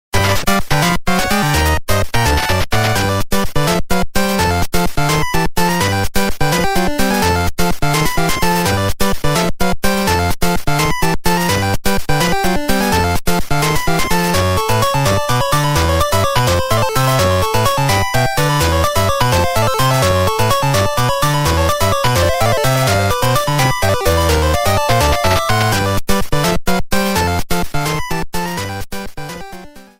Self-recorded